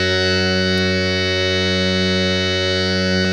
52-key03-harm-f#2.wav